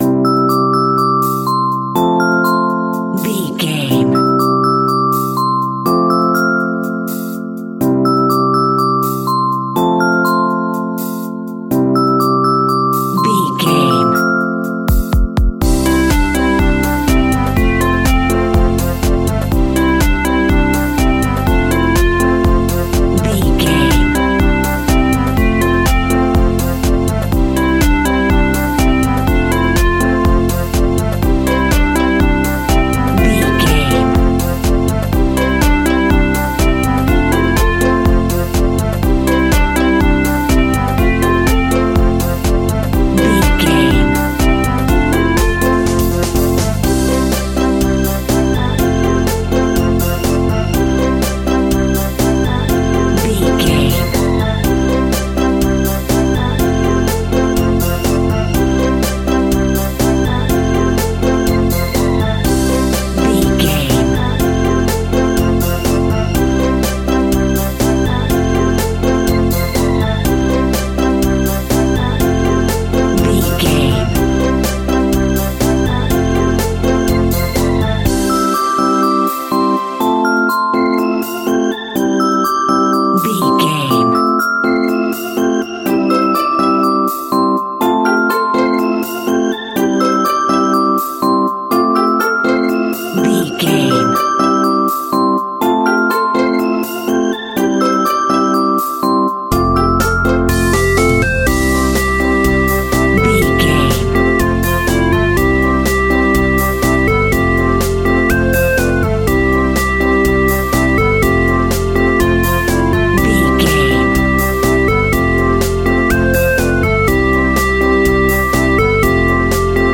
Uplifting
Ionian/Major
Fast
instrumentals
childlike
cute
happy
kids piano